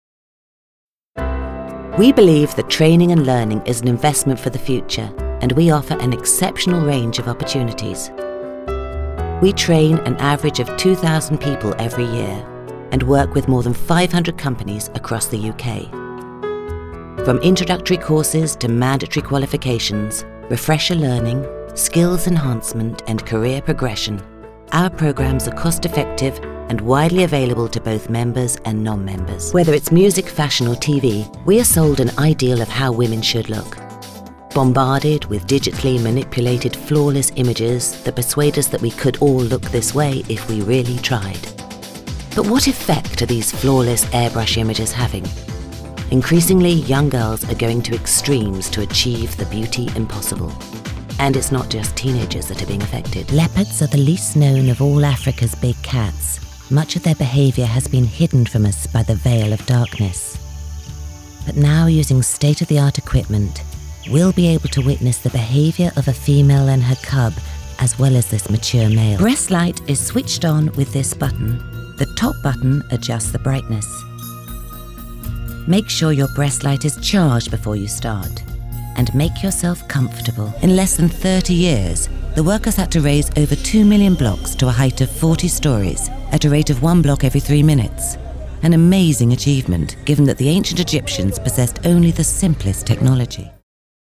Narrative showreel
Experienced award-winning, stage, screen and voice actor based in London.